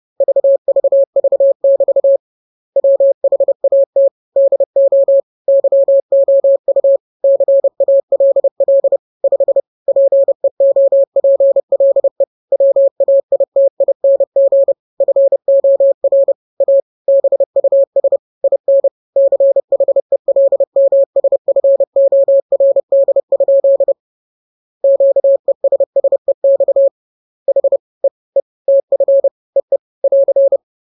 You find below a collection of ham/CW-themed Christmas-Cracker jokes.
The jokes have been converted in CW using ebook2cw by Fabian DJ5CW. I went for some usual speeds, but I can definitely add more if you like.